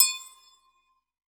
EAGOGO LW.wav